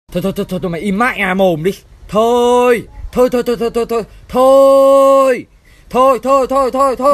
Thể loại: Câu nói Viral Việt Nam
Giọng điệu thể hiện được là không tin được điều mà người đối diện vừa nói với mình theo phong cách hài hước...